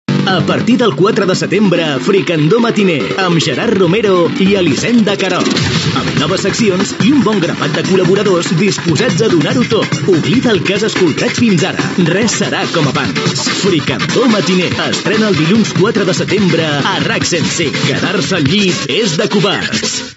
Promoció del programa, amb els nous presentadors